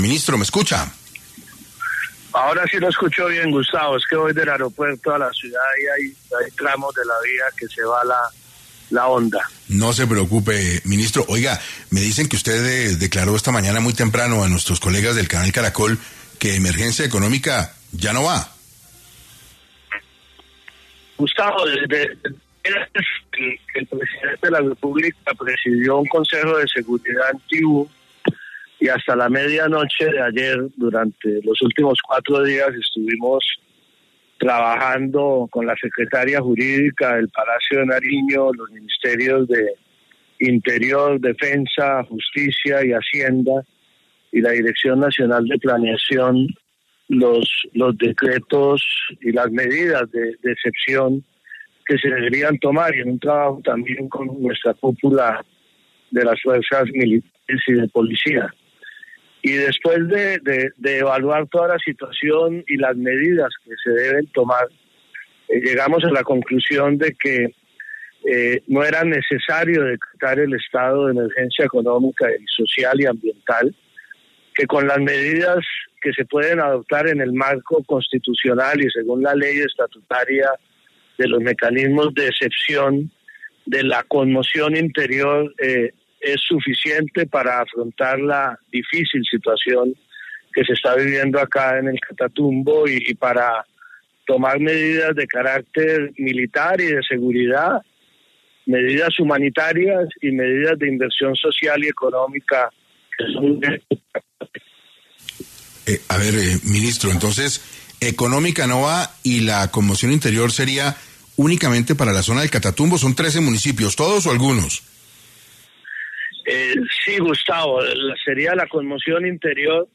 En 6AM de Caracol Radio estuvo el ministro del Interior, Juan Fernando Cristo, para hablar sobre por qué decidieron descartar la emergencia económica para atender crisis humanitaria en el Catatumbo.